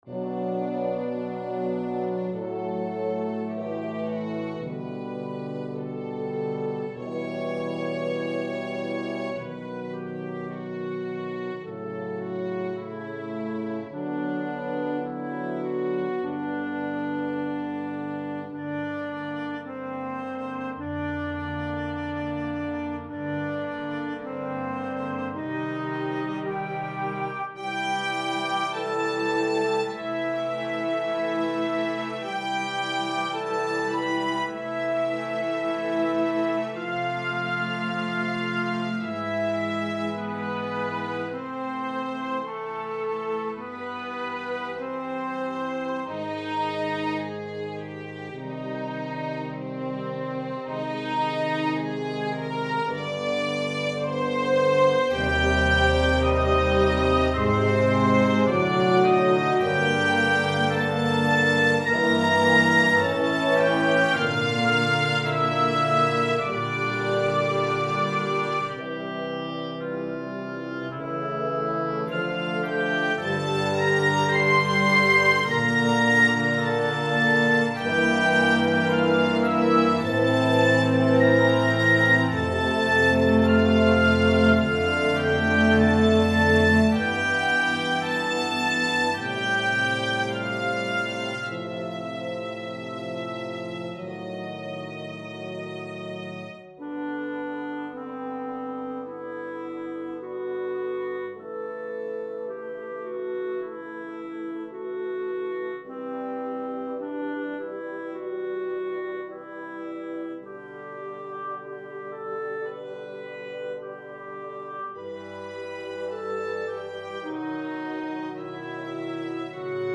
It’s entirely played by orchestral virtual instruments, as i am unable to play ANY instrument myself!
Op1KvH-Tinyest-song-Orchestral.mp3